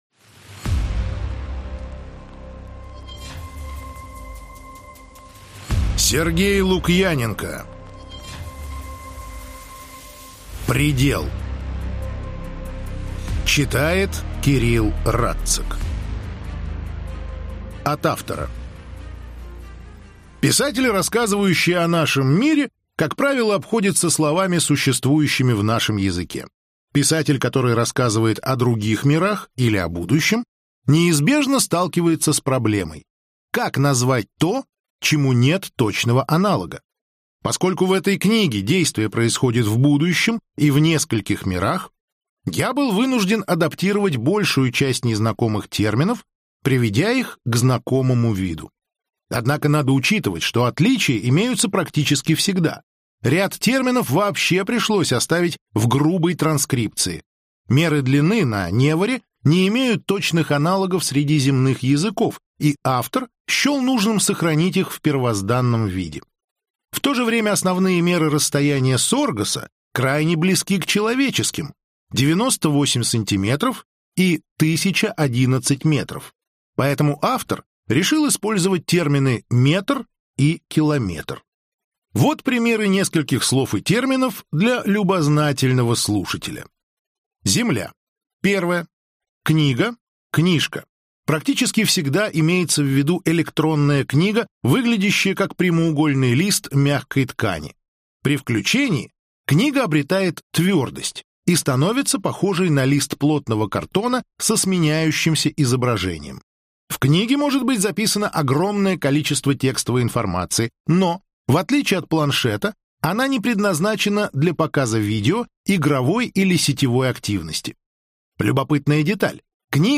Аудиокнига Предел - купить, скачать и слушать онлайн | КнигоПоиск